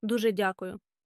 DOO-zheh DYAH-koo-yoo thank you very much